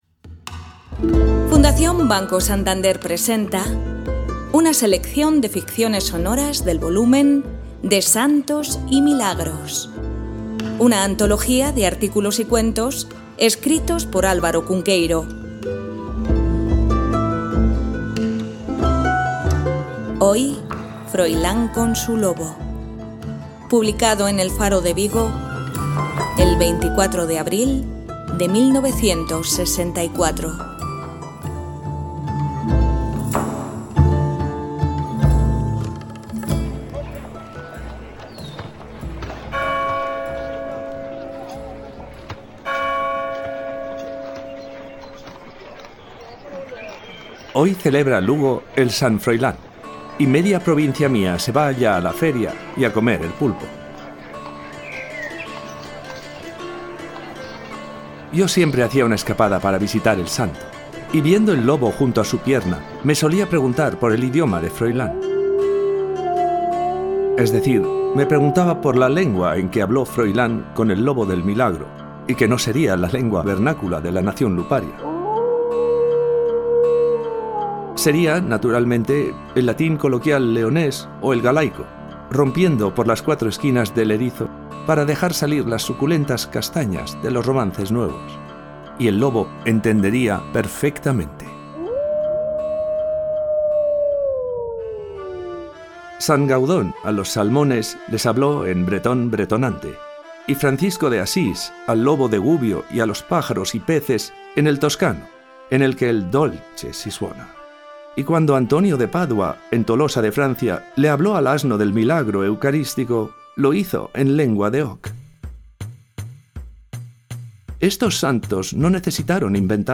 Ficciones sonoras